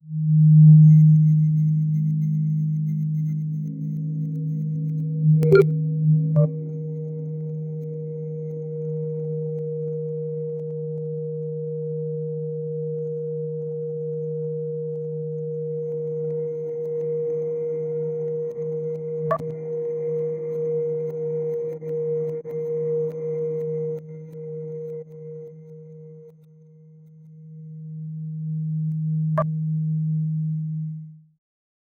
Design a reactive sound set for user interaction: each touch triggers evolving tones that morph over time—e.g., from synthetic sine tones to soft ambient textures, with subtle pitch bends and envelope shaping to give a sense of movement and growth. 0:32